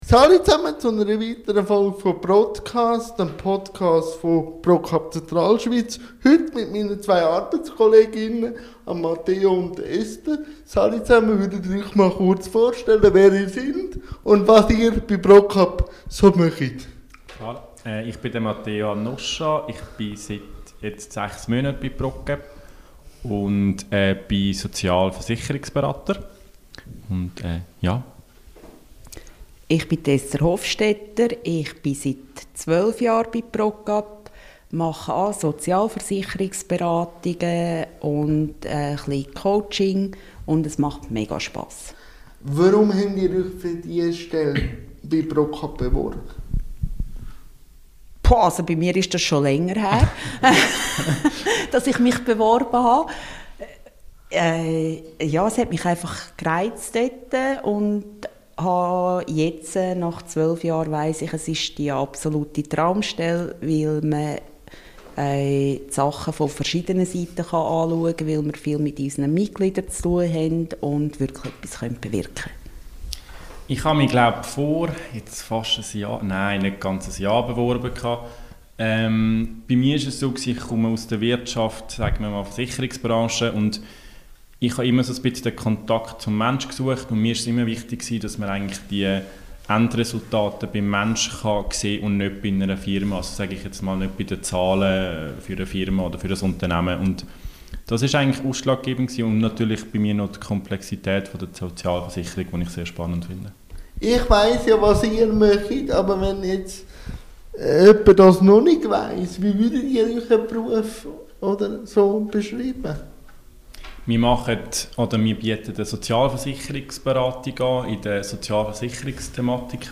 P(R)ODCAST: Talk über Sozialversicherungsberatung